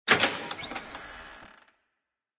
dooropened-103851.mp3